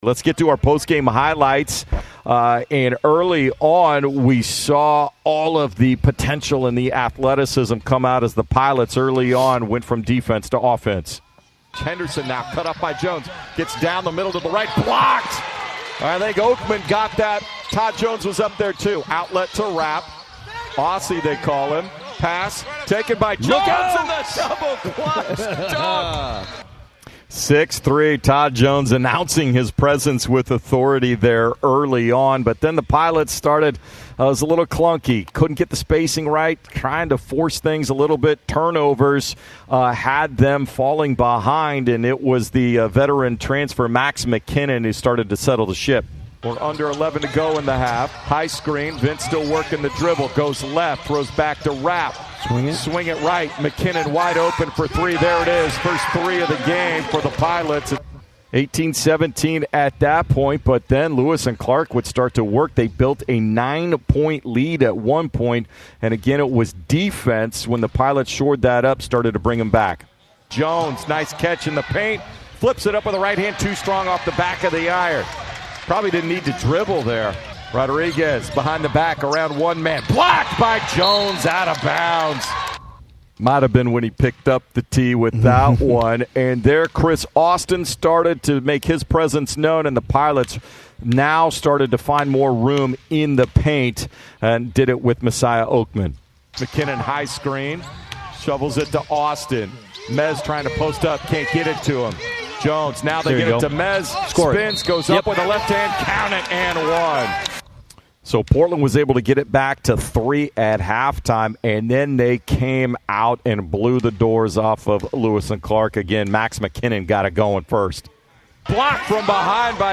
Men's Hoops Radio Highlights vs. Lewis & Clark